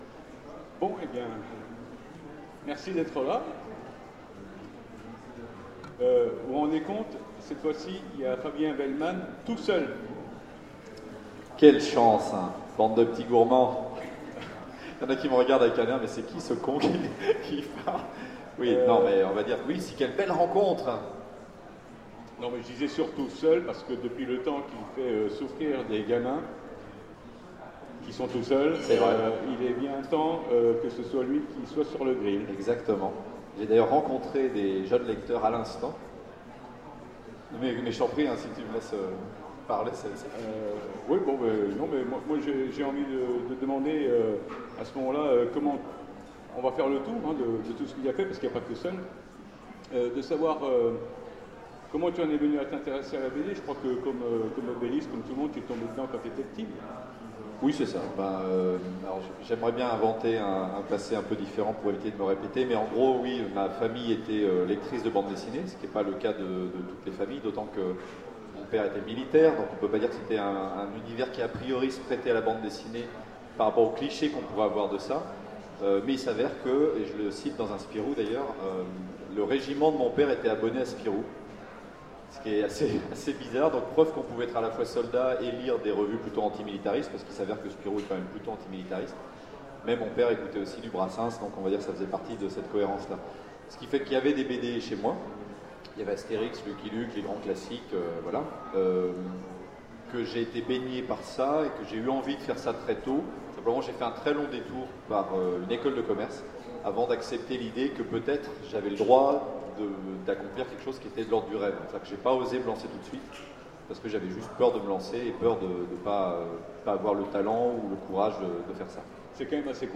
- le 31/10/2017 Partager Commenter Utopiales 13 : Conférence Rencontre avec Fabien Vehlmann Télécharger le MP3 à lire aussi Fabien Vehlmann Genres / Mots-clés Rencontre avec un auteur Conférence Partager cet article